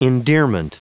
Prononciation du mot endearment en anglais (fichier audio)
Prononciation du mot : endearment